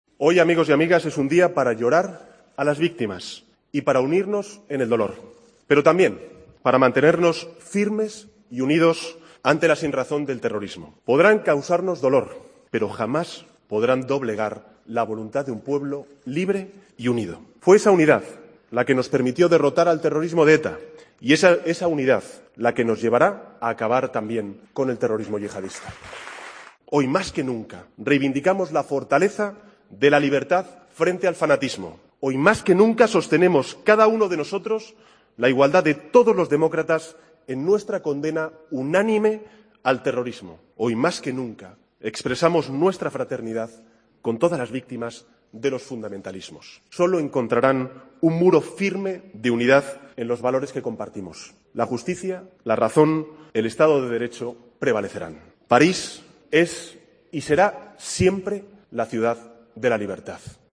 Pedro Sánchez en la Conferencia Política del PSOE